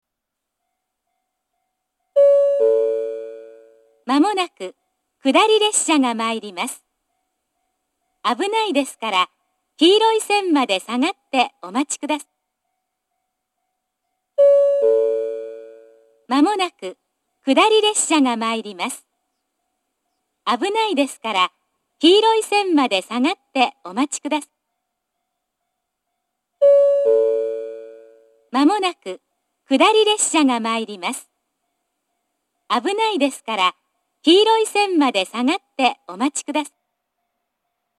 なお、２番線の接近放送は何故か最後の部分が欠けます。
２番線接近放送 篠ノ井からの距離が短いので発車案内、接近予告、接近放送と連続で流れることが多いです。